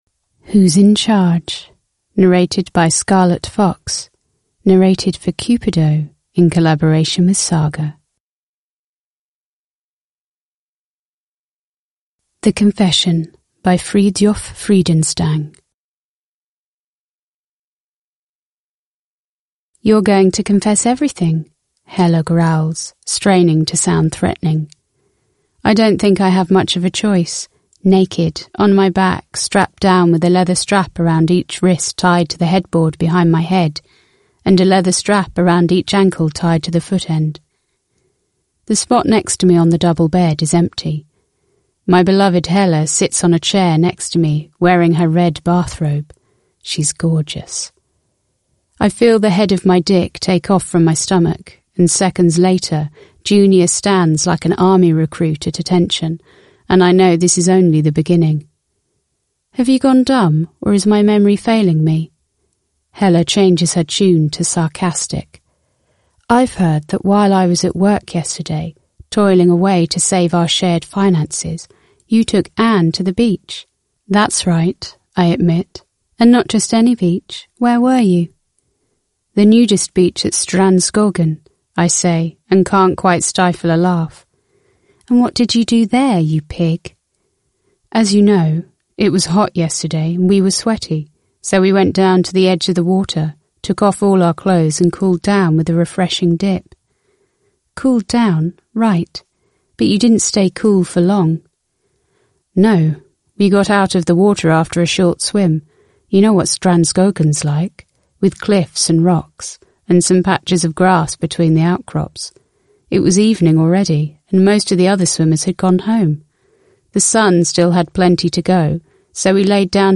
(ljudbok) av Cupido